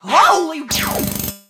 jackie_drill_hurt_vo_04.ogg